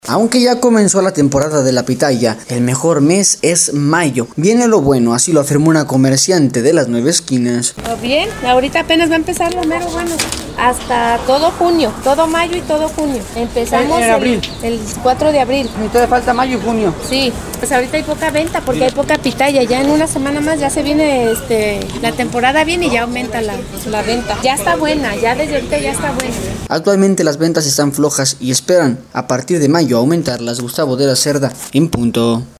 Aunque ya comenzó la temporada de la pitaya, el mejor mes es mayo, viene lo bueno, así lo afirmó una comerciante de las nueve esquinas: